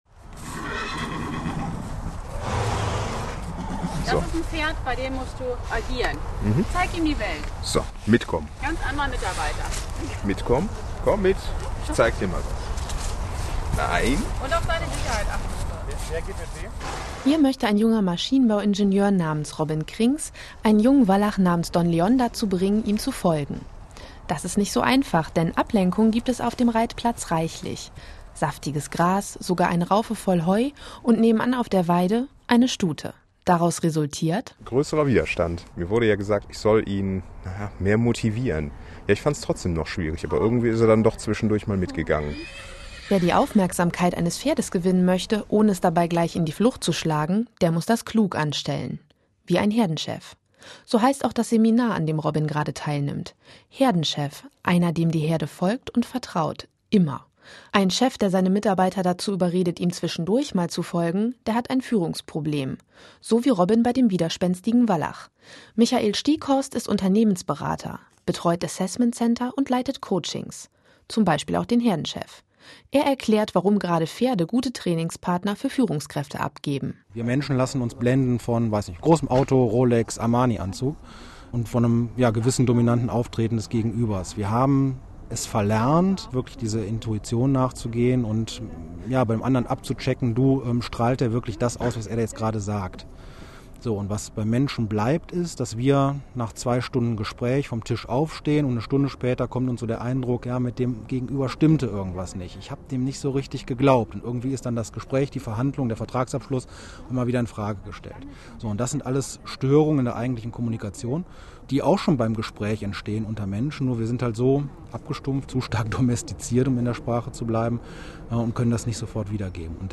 SWR2_Reportage.mp3